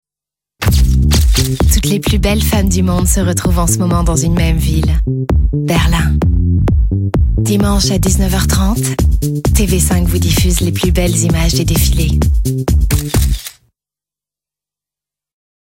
Comédienne et chanteuse,je fais des voix régulièrement (doublage,pub,habillage radio et TV) parallèlement à mes activités sur scène.
Sprechprobe: Werbung (Muttersprache):